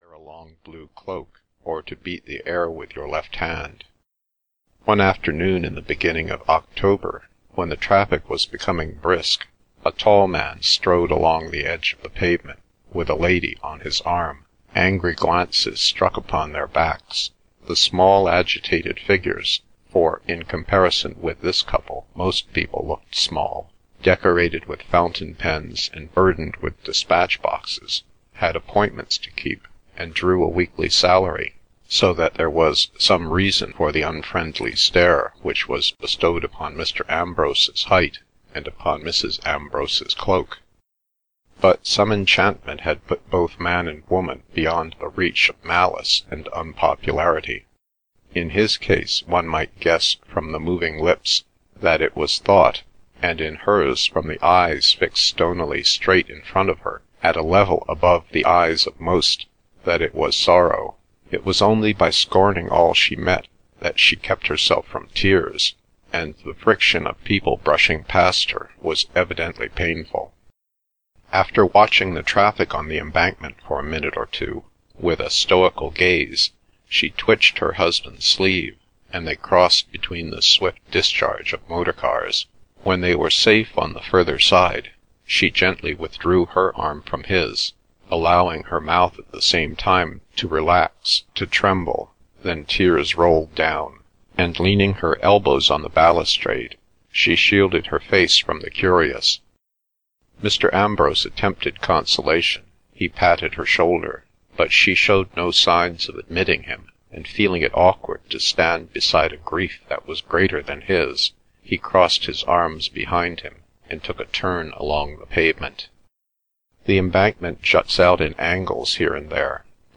The Voyage Out (EN) audiokniha
Ukázka z knihy